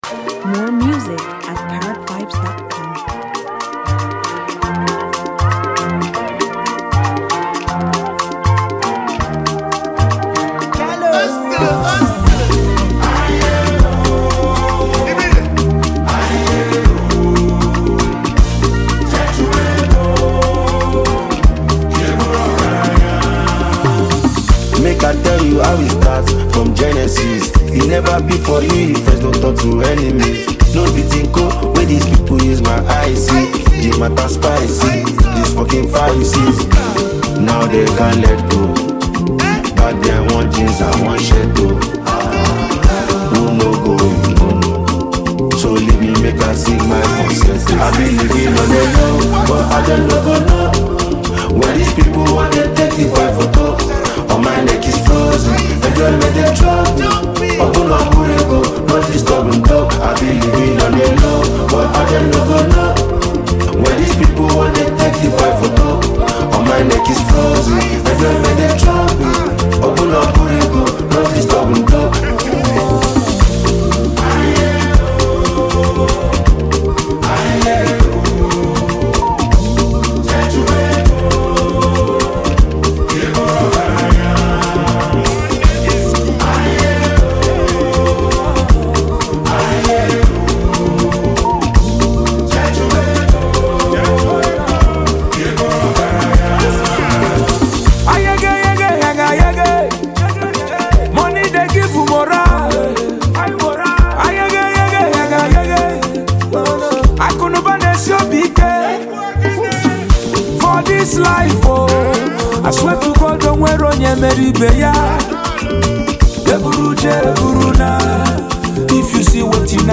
Inimitably skilled Nigerian rapper, lyricist, and performer